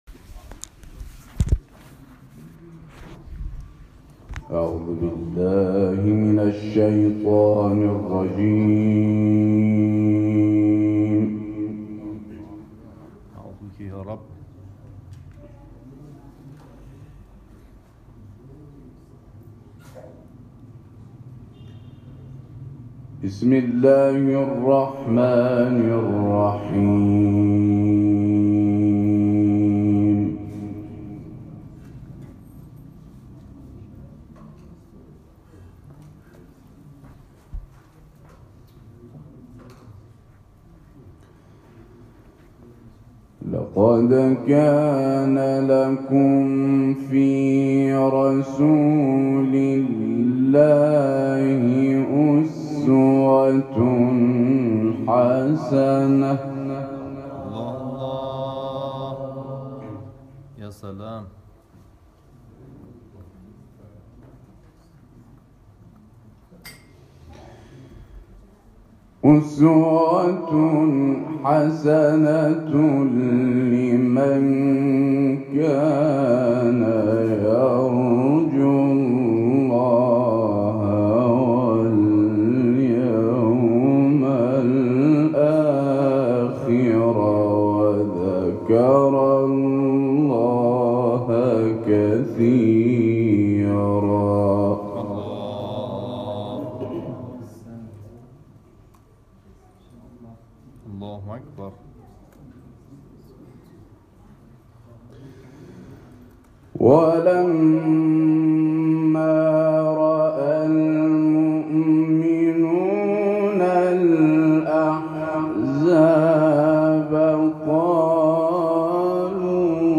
تلاوت
در بندر لنگه